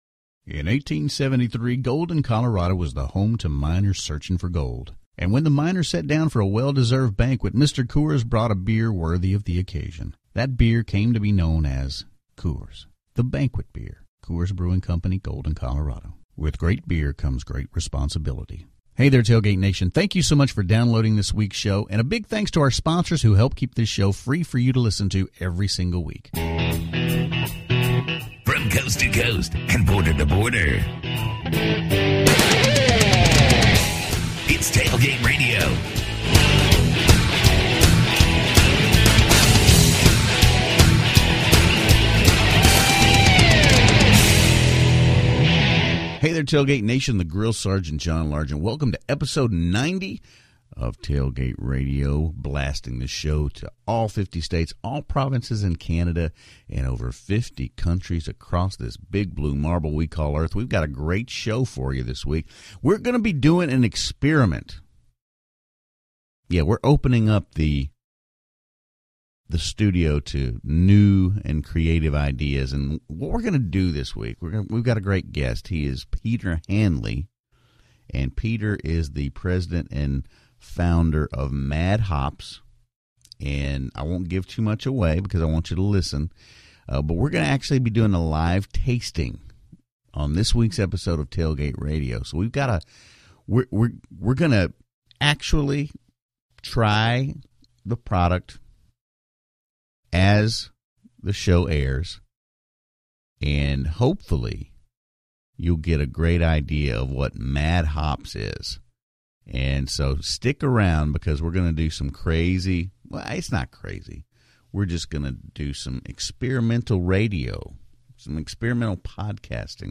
We conduct a live tasting and to learn more about this really fantastic product!